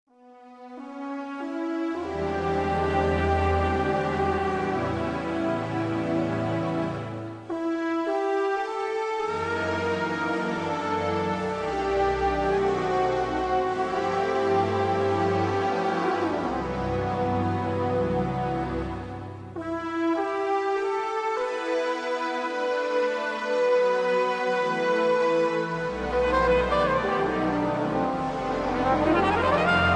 backing tracks
rock, easy listening, blues